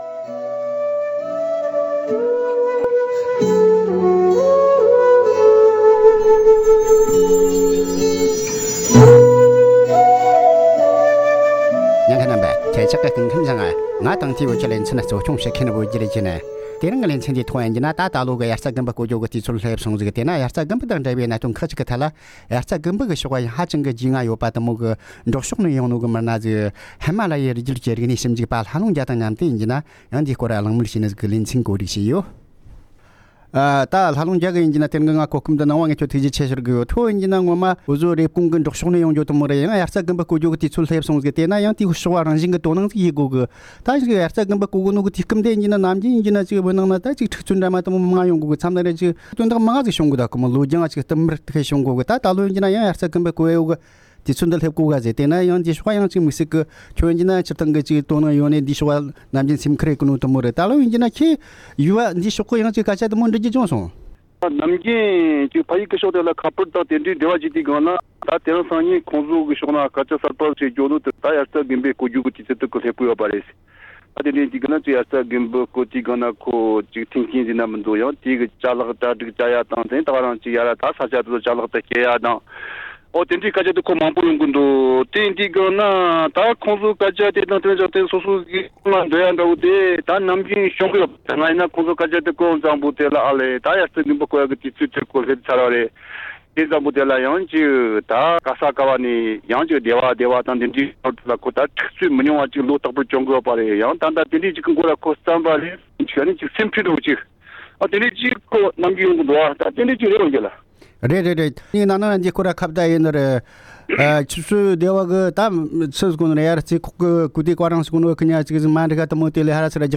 དབྱར་ཙྭ་དང་འབྲེལ་བའི་གནད་དོན་ཁག་གི་ཐད་འབྲེལ་ཡོད་མི་སྣ་དང་གླེང་མོལ།